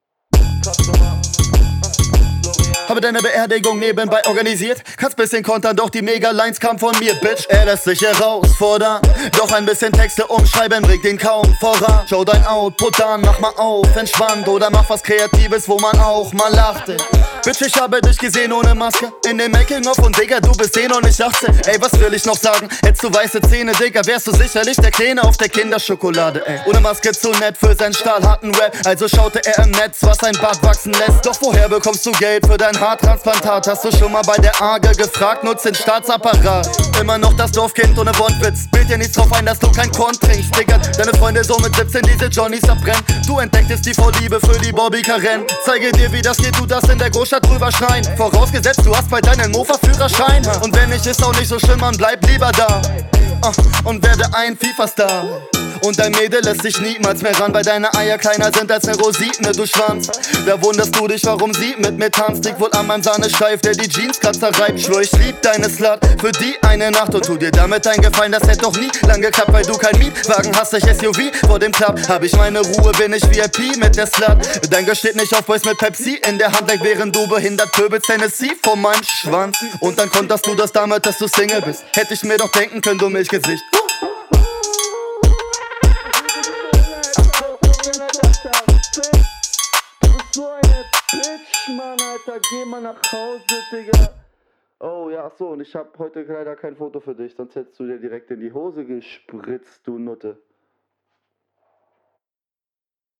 Schöner Floweinstieg.
Beat ist geil.